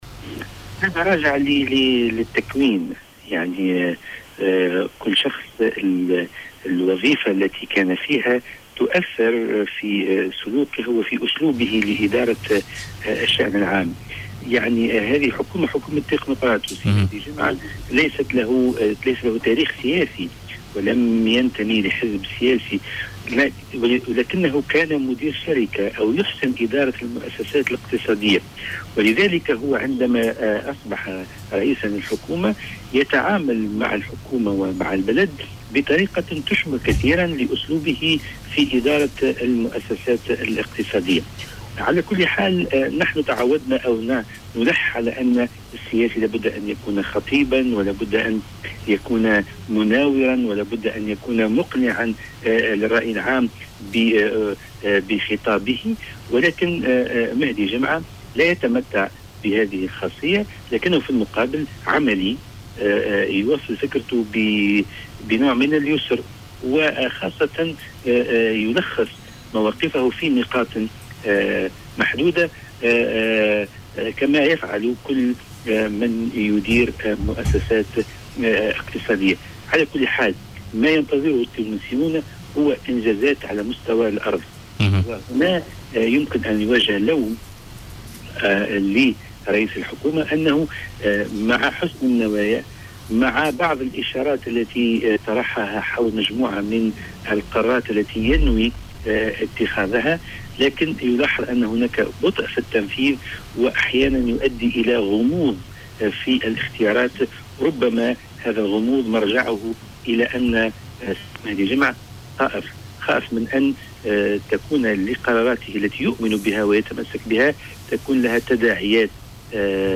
L’analyste politique Slaheddine Jourchi a indiqué, dans une intervention sur les ondes de Jawhara FM, mercredi 7 mai 2014, dans le cadre de l’émission Politica, que Mehdi Jomâa est un professionnel qui gère le pays comme « un homme d’affaires » non comme un politicien.